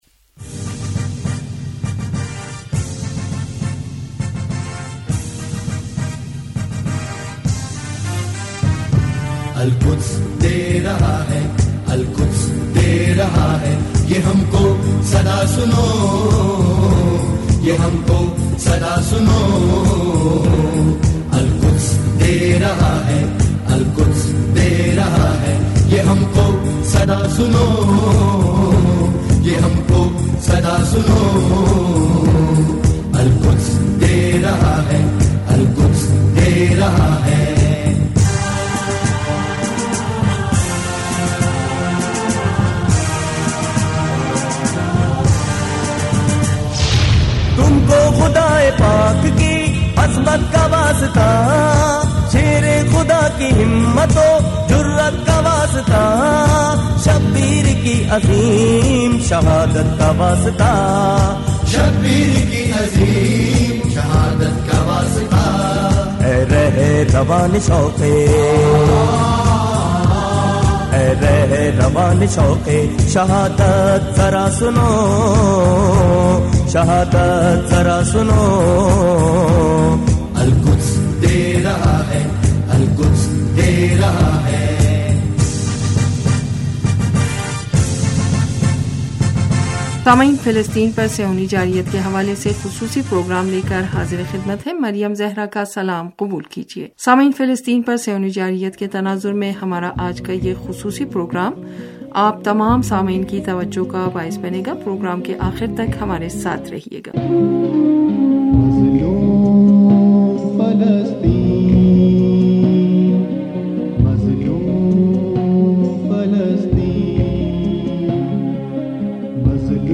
ریڈیو کے خصوصی پروگرام